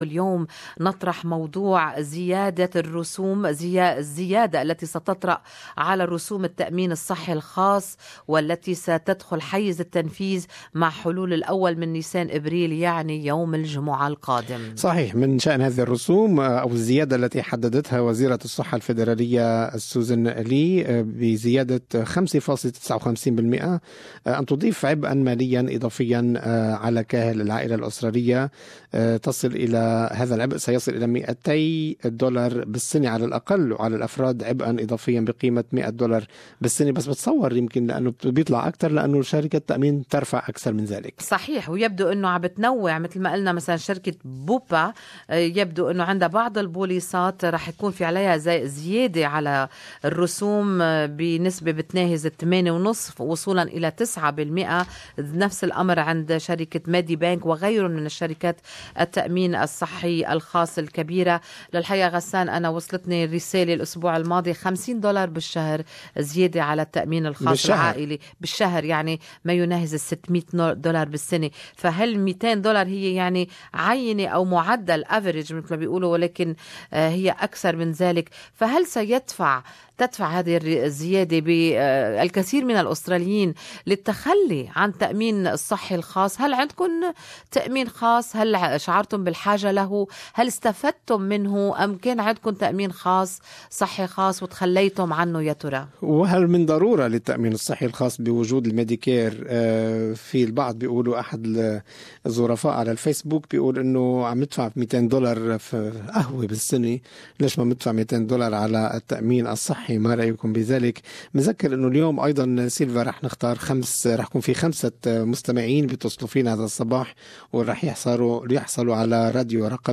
Talkback listeners opinions of Increase fees for private health insurance.